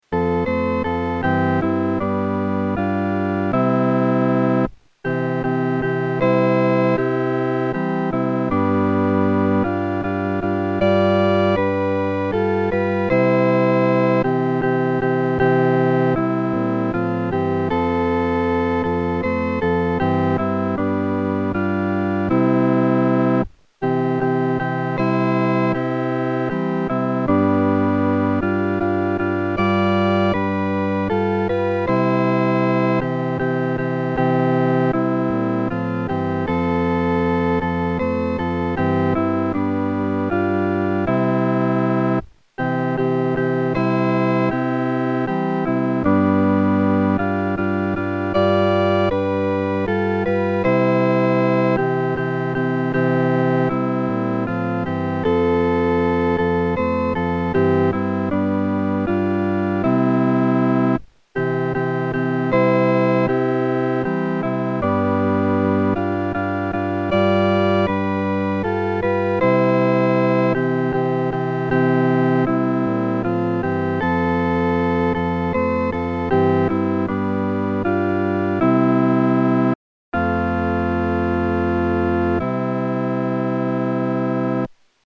伴奏
四声
其旋律、和声构成无比宁静的气氛，在丧礼中给人莫大的安慰。